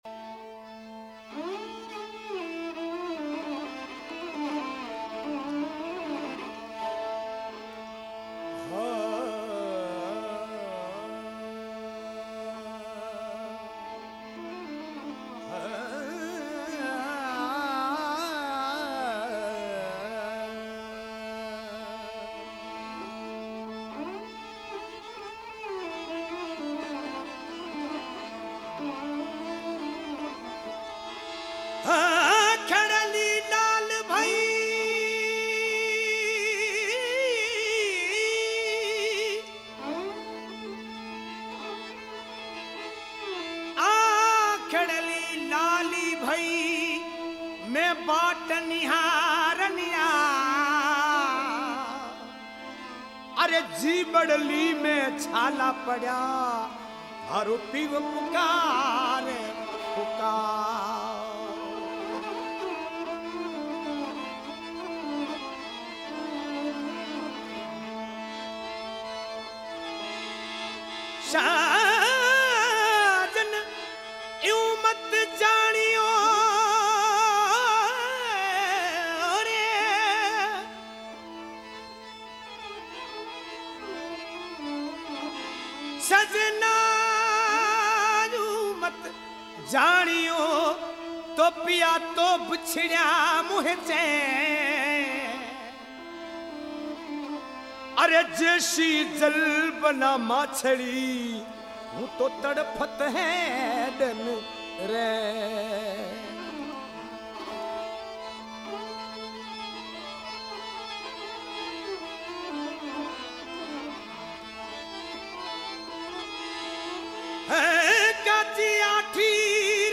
[Folk]